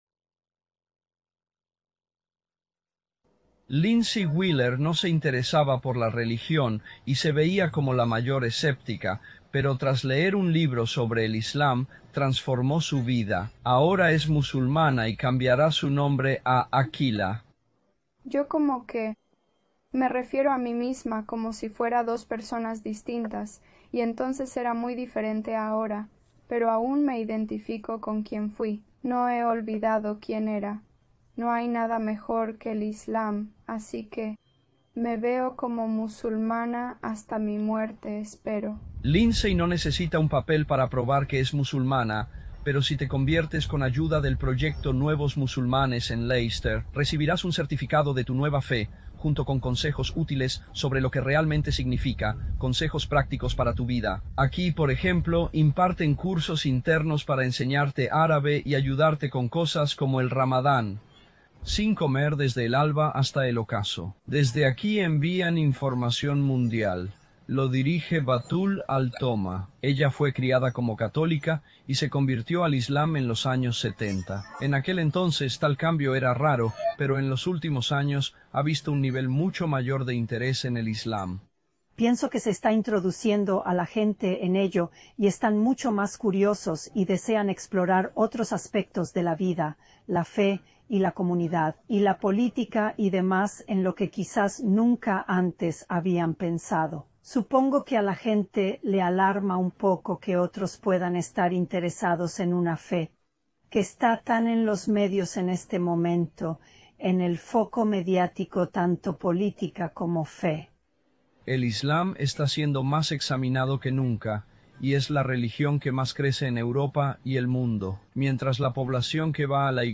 Descripción: En este reportaje de Five News, los periodistas explican cómo el Islam se ha convertido en la religión de más rápido crecimiento en el Reino Unido y en Europa en general.